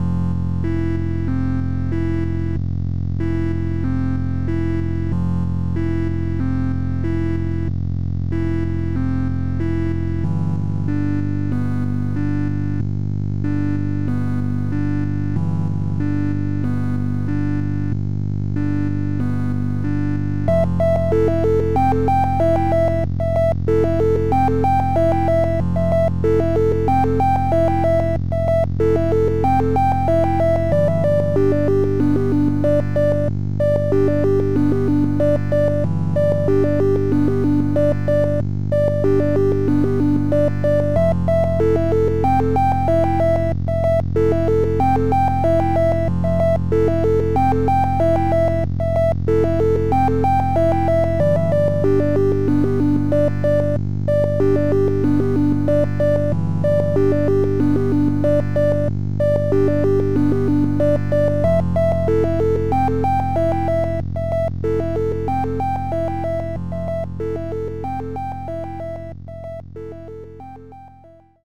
93bpm